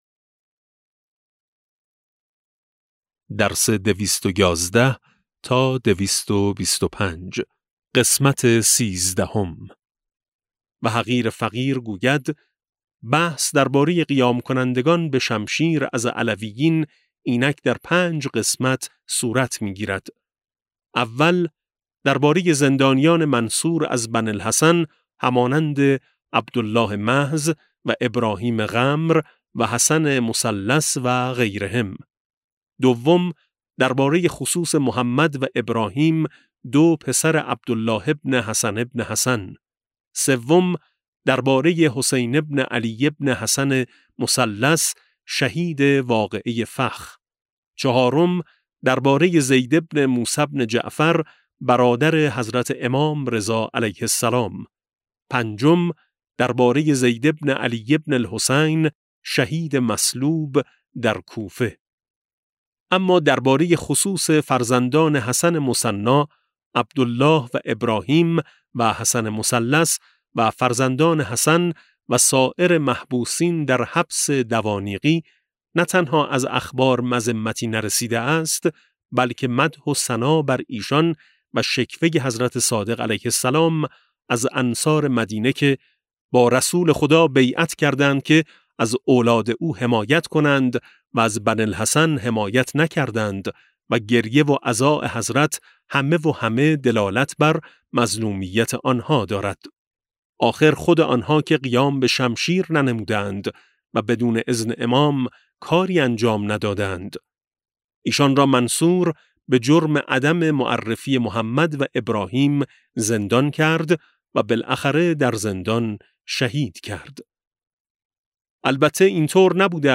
کتاب صوتی امام شناسی ج15 - جلسه13